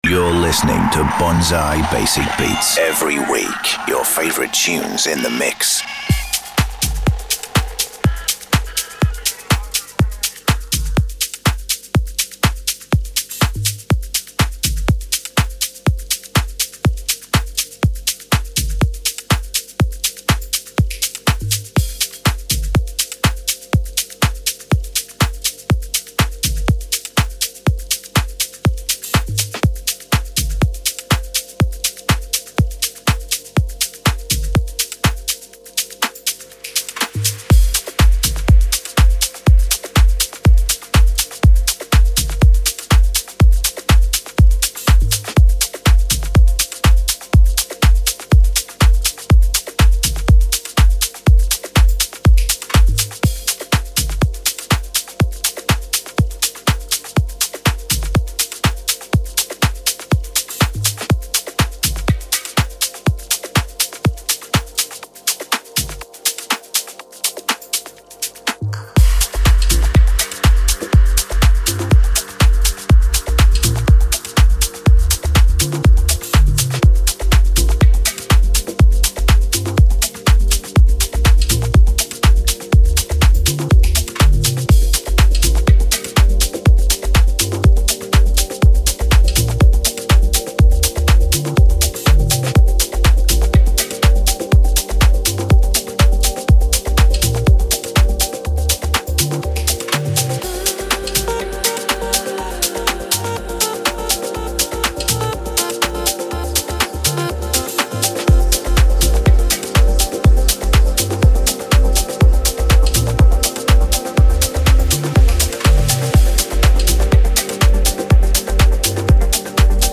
your weekly showcase of DJ talent from around the globe.